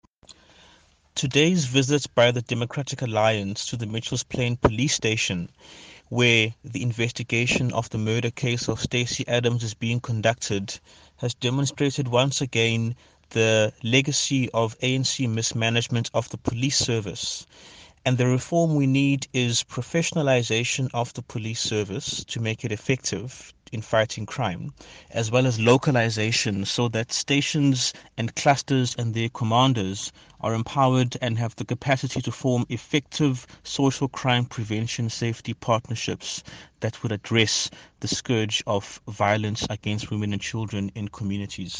Issued by Zakhele Mbhele MP – DA Shadow Minister of Police